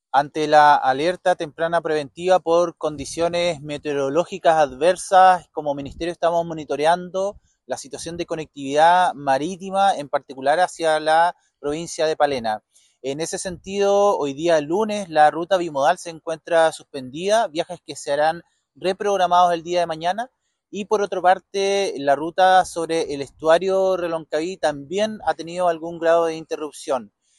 En tanto, el Seremi de Transportes, Pablo Joost, explicó que la ruta bimodal se encuentra suspendida y los viajes serán reprogramados.